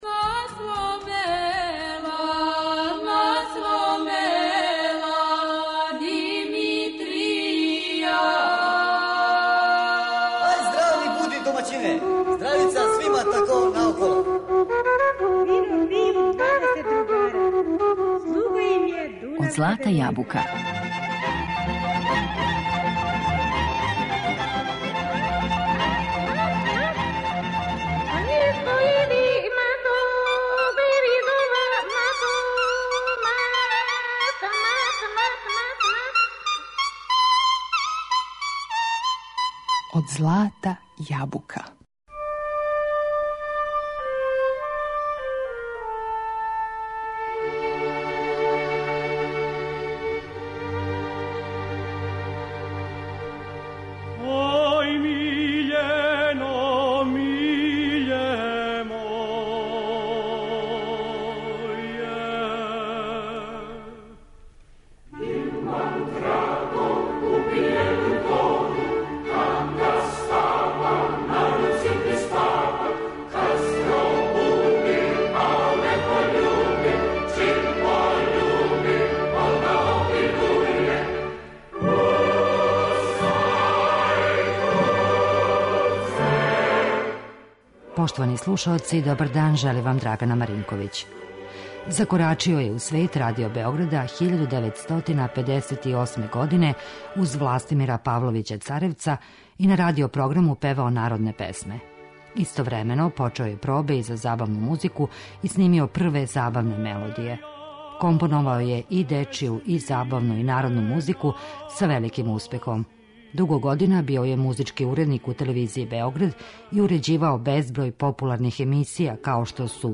Данас представљамо његов композиторски рад и слушамо градске и народне песме које је компоновао и које се и данас радо и често изводе и слушају.